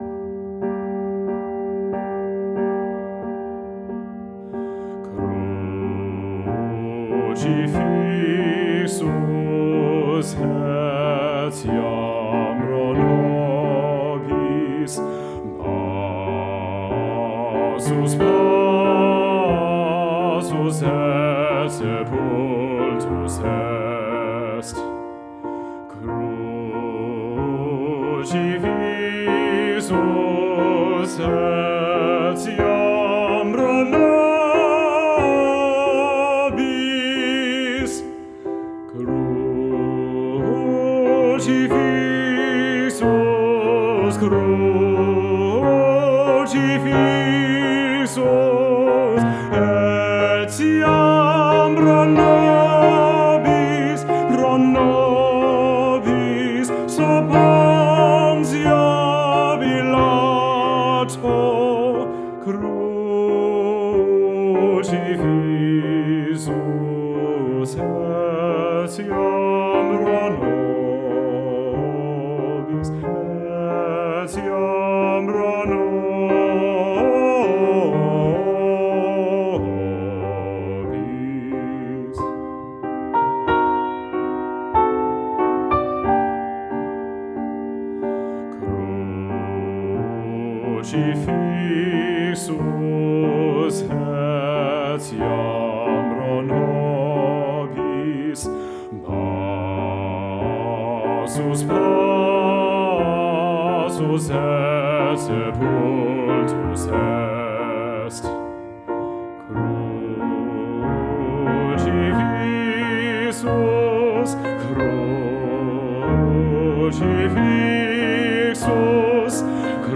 Solo Voice
Classical:
Voice
Piano